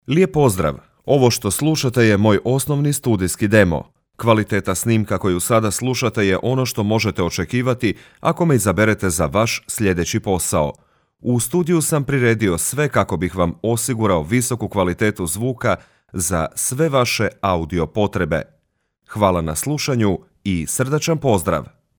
Experienced voice-over talent for Croatian, Bosnian and Serbian market.
Sprechprobe: Werbung (Muttersprache):